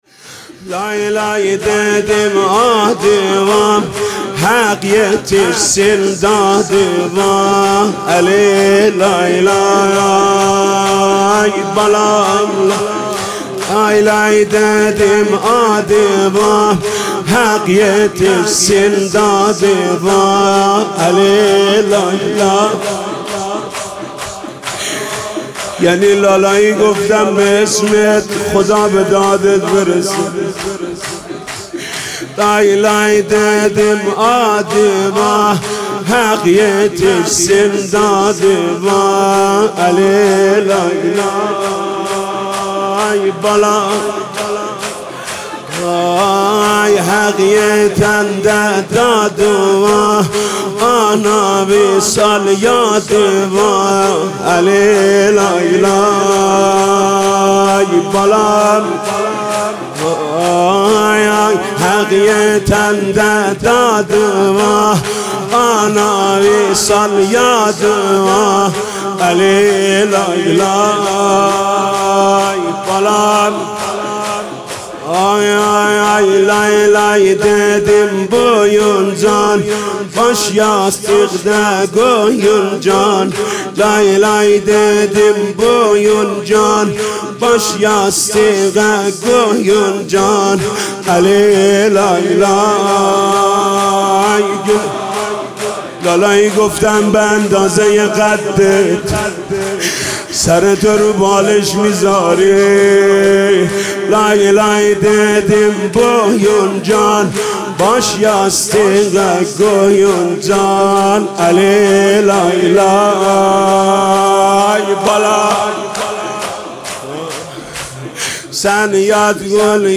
مداحی ترکی محمود کریمی در شب هفتم محرم؛ لای لای ددیم آدیوا حق یتیرسین دادیوا